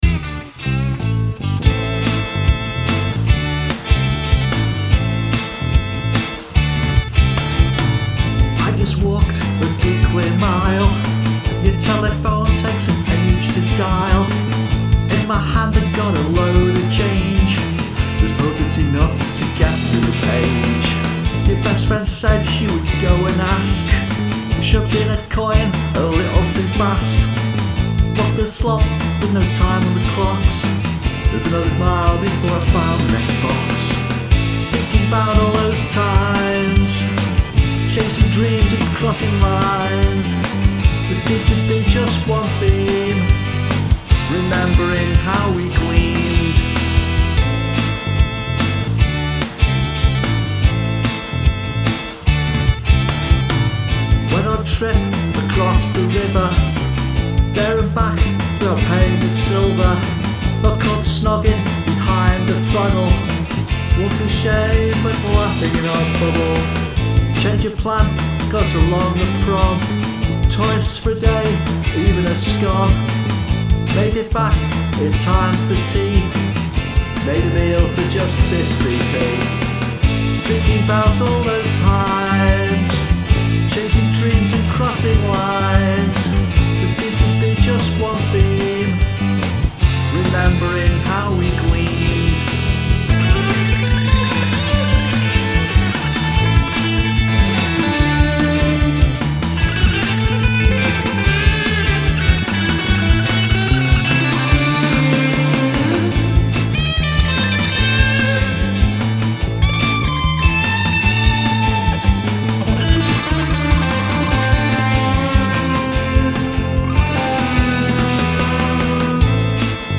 A little psychedelic pop